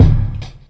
Kick 7.wav